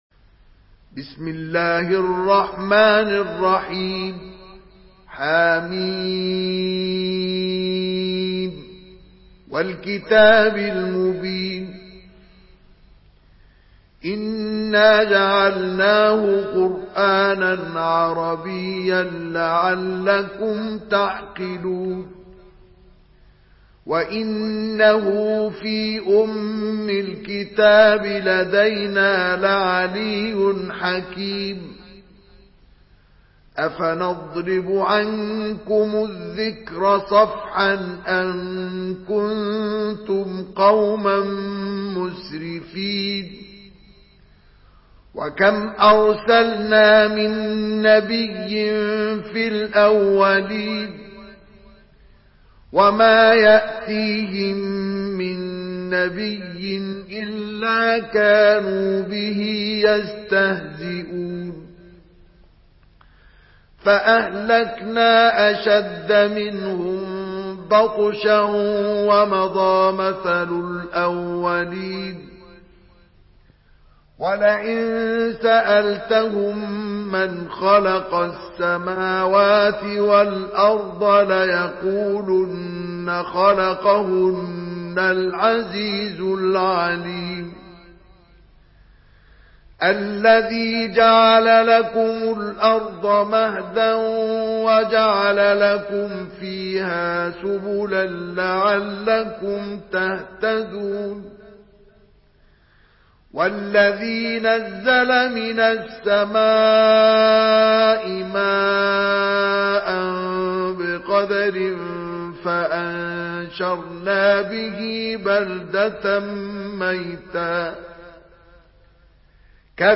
Surah الزخرف MP3 in the Voice of مصطفى إسماعيل in حفص Narration
مرتل